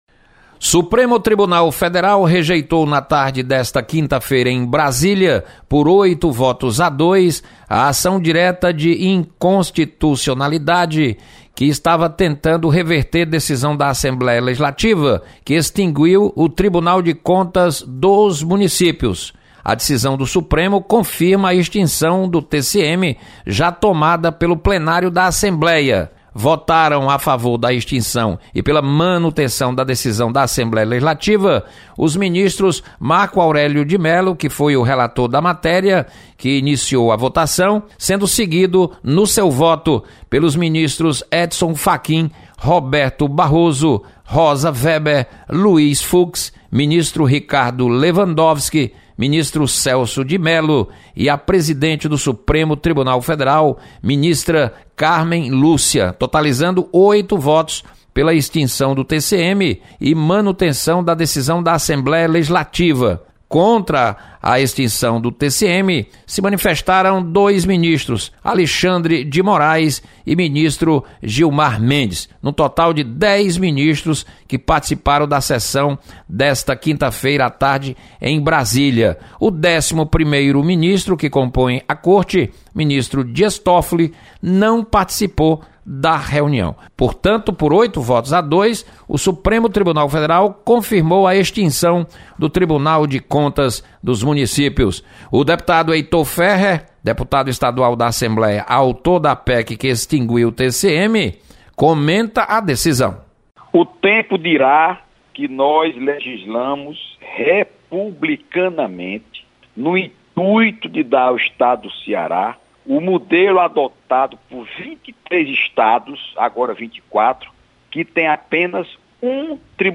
Maioria do STF decide pela extinção do TCM. Repórter